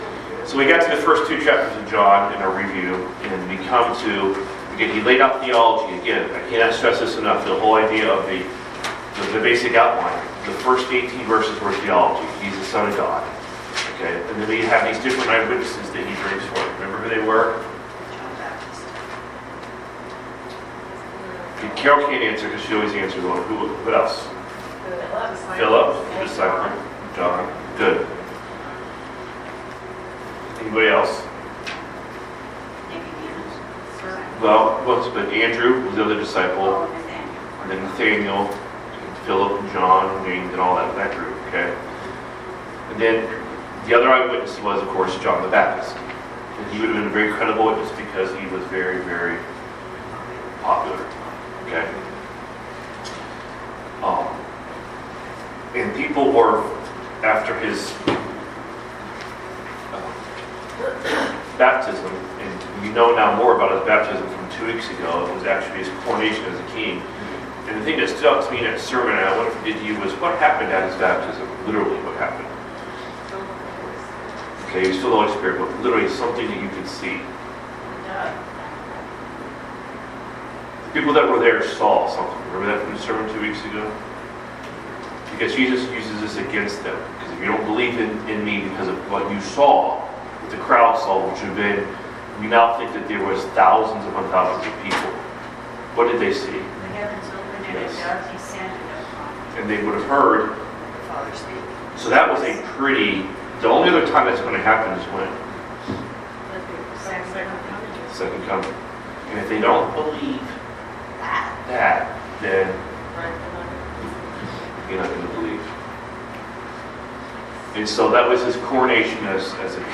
Sunday School from Bible Chapel of Auburn, WA